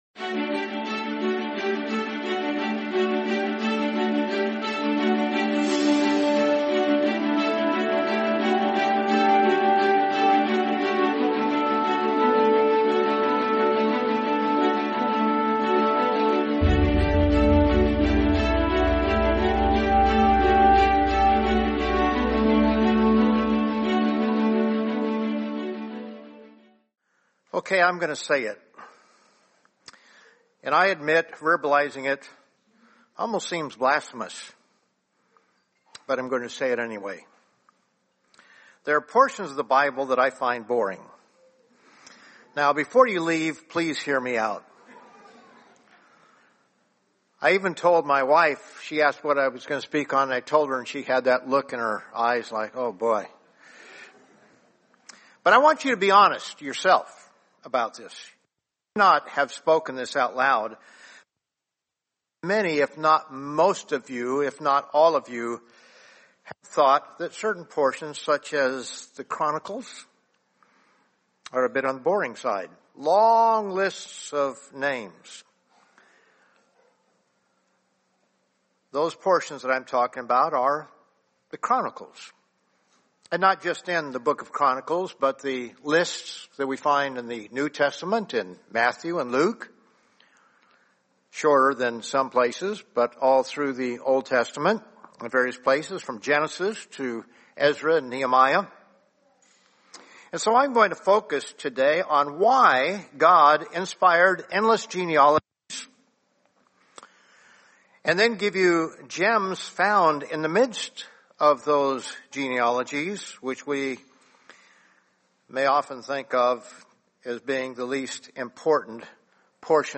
Sermon Why Endless Genealogies